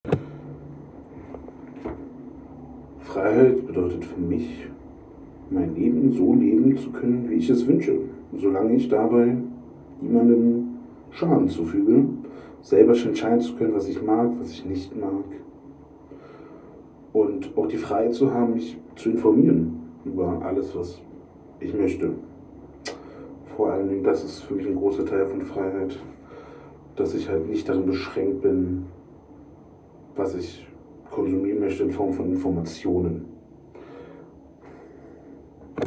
Standort der Erzählbox:
Computerspielemuseum @ Berlin